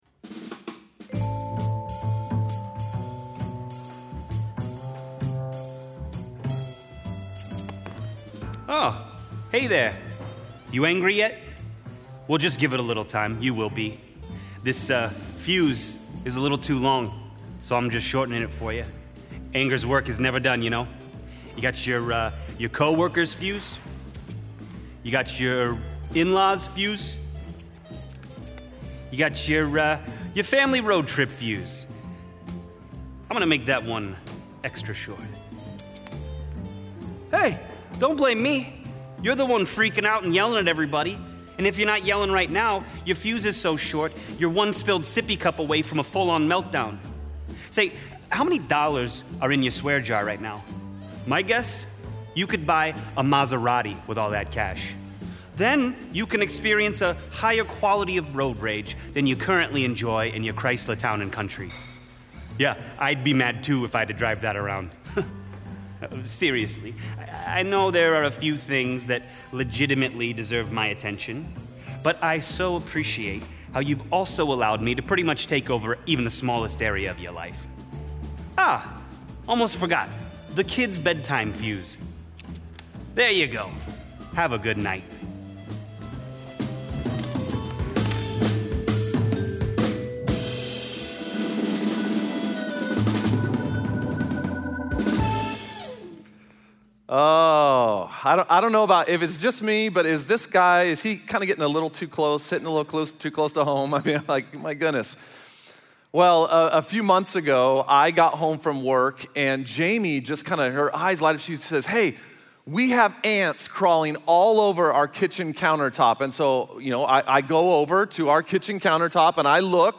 Message Only